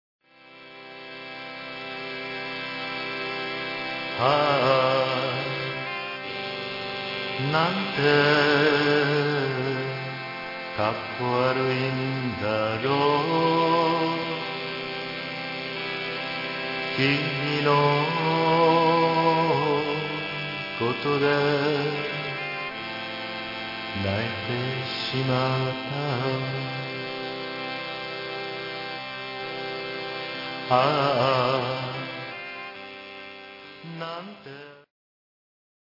大阪の誇る孤高のシンガー